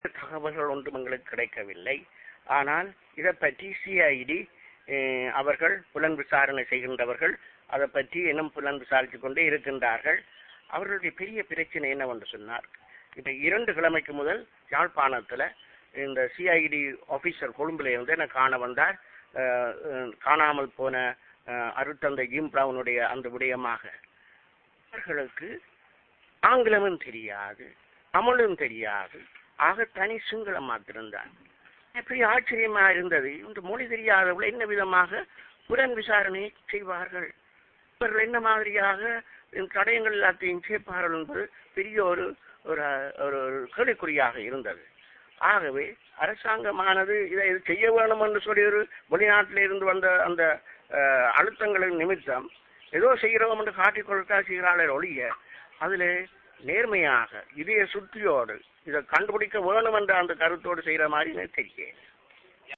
Voice: Jaffna Bishop on the investigation of
The Jaffna Bishop Rt. Rev. Dr. Thomas Savundranayagam, speaking to the media in Ki'linochchi further said that "Peace cannot be achieved in Sri Lanka by military means, and any attempts to find a solution by such methods will only end up further aggravating the sufferings of the people."